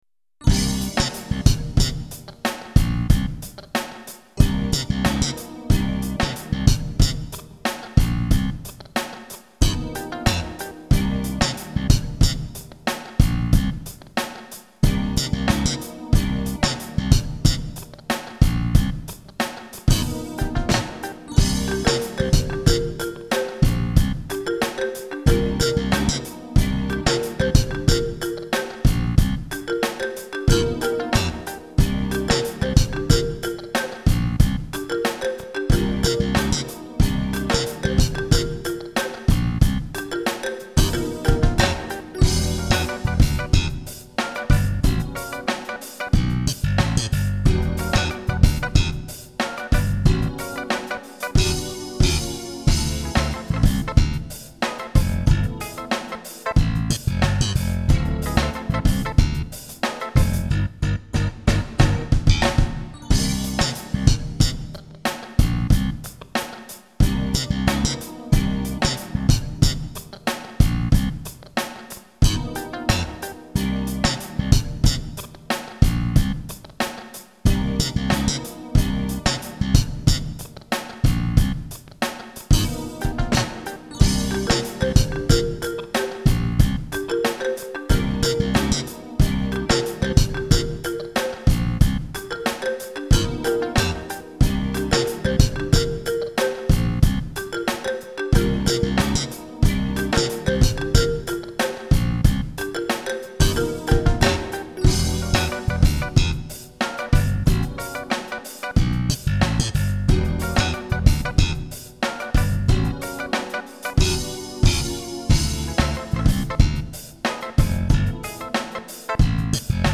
Roland Fantom XR Version